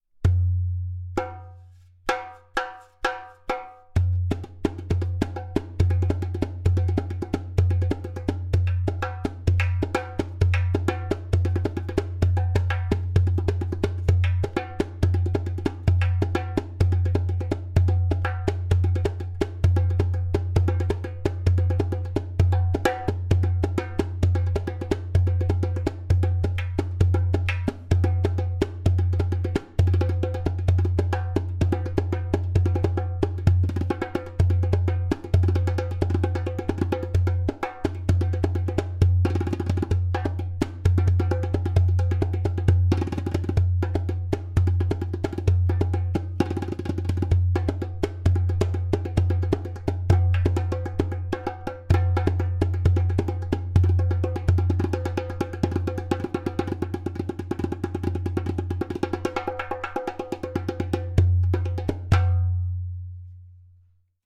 Premium Bronze 9.5 inch darbuka with goat Skin
130 bpm:
• Taks with harmonious overtones.
• Even tonality on around rim.
• Deep bass.
• Loud clay kik/click sound!